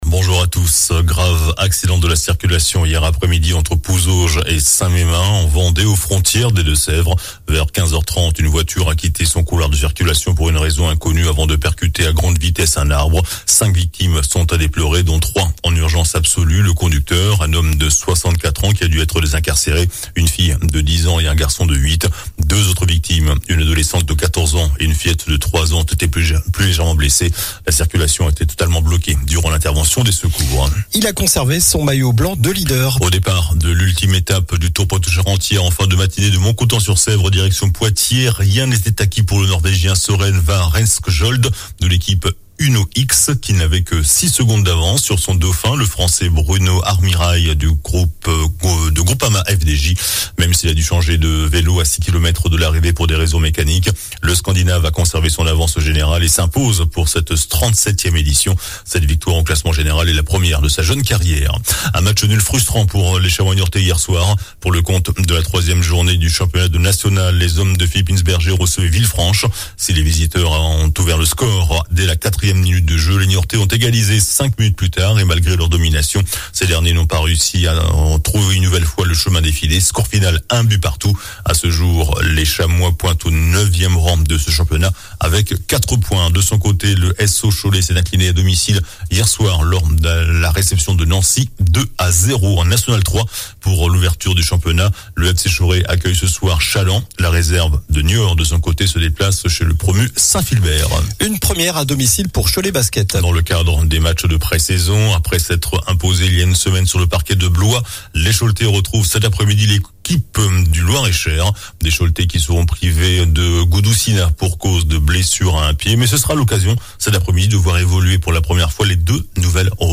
JOURNAL DU SAMEDI 26 AOÛT